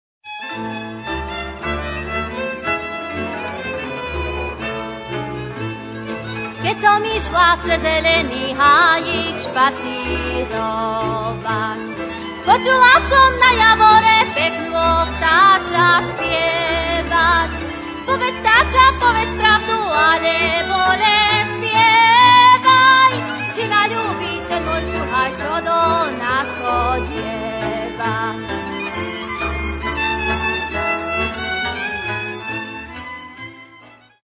1. husle
kontry
cimbal
Kategória: Ľudová hudba